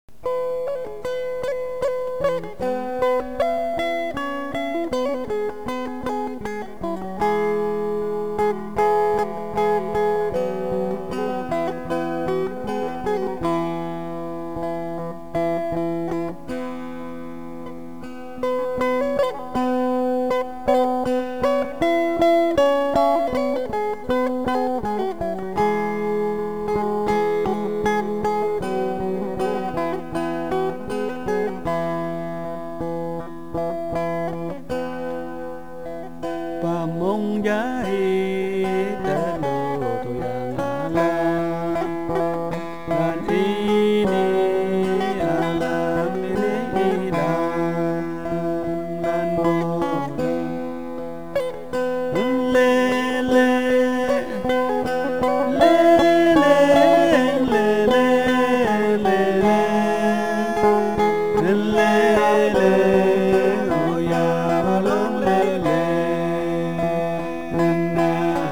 Its intricate rhythms may also be inspired by dreams.